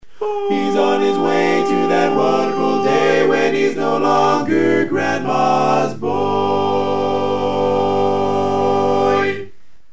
Key written in: G Major
Type: Barbershop